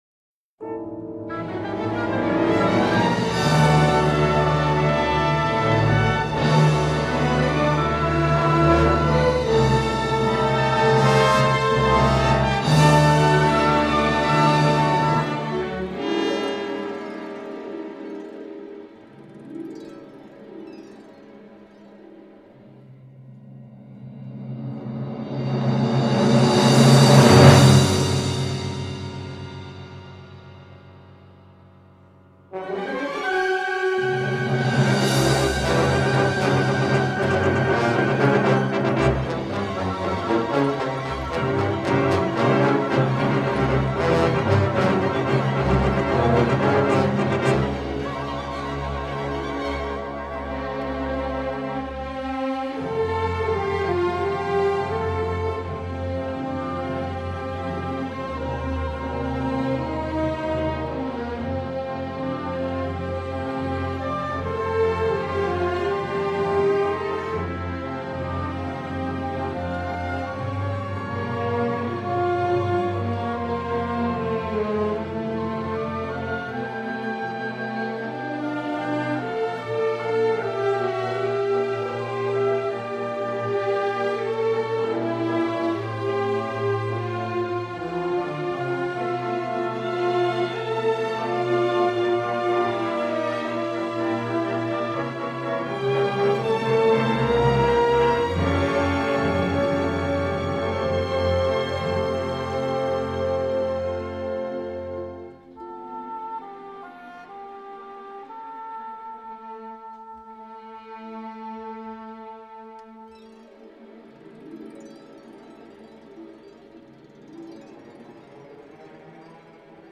Délicieusement vintage.